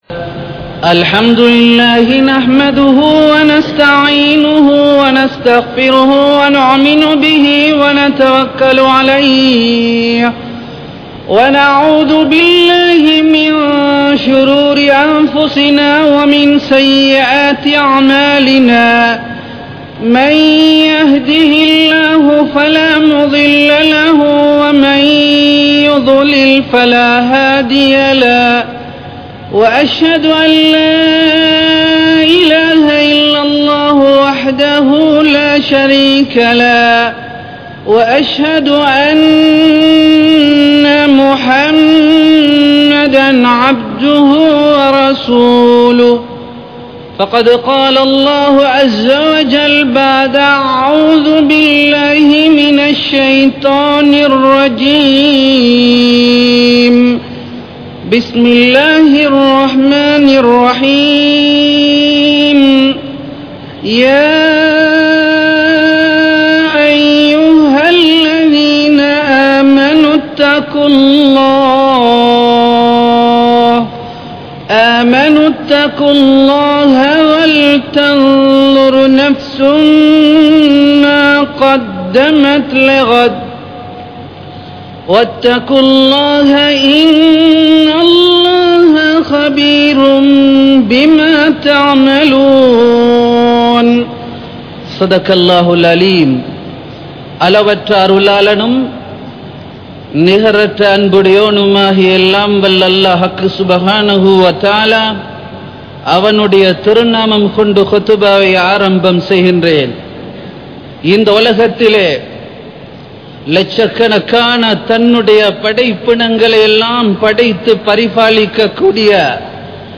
Naavai Paathuhaarungal | Audio Bayans | All Ceylon Muslim Youth Community | Addalaichenai